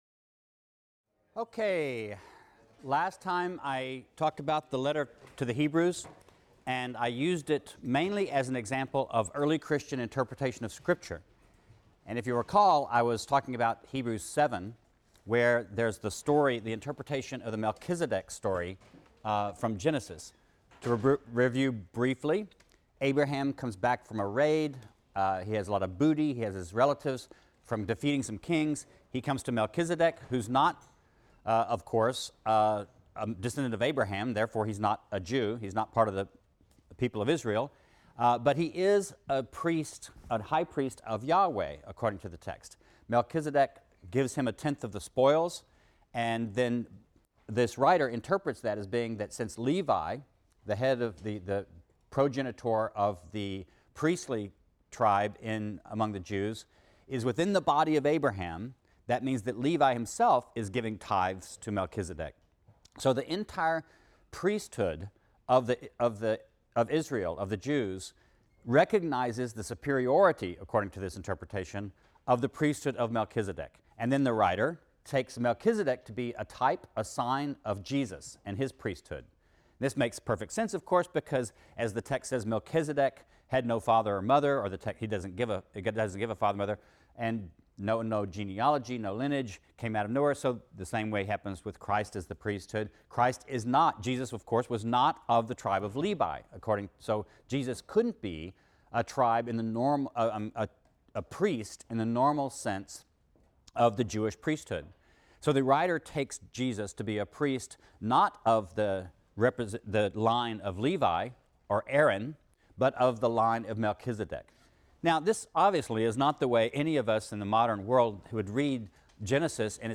RLST 152 - Lecture 22 - Interpreting Scripture: Medieval Interpretations | Open Yale Courses